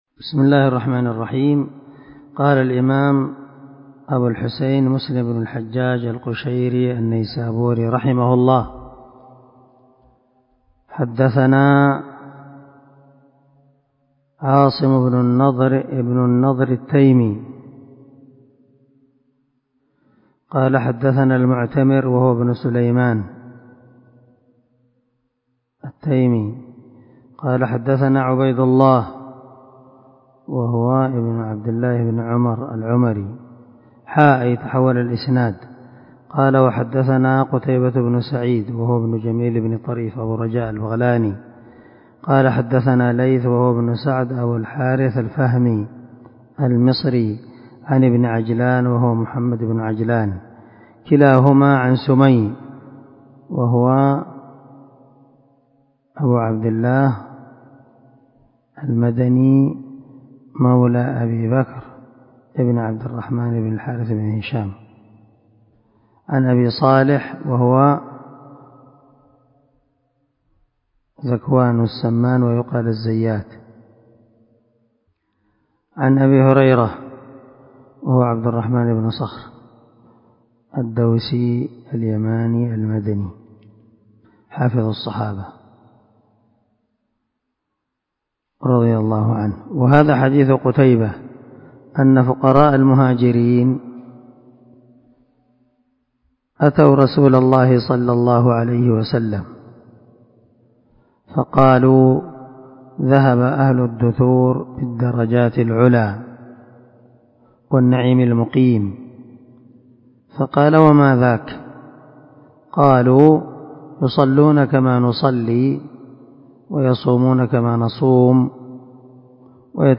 377الدرس 49 من شرح كتاب المساجد ومواضع الصلاة حديث رقم ( 595 ) من صحيح مسلم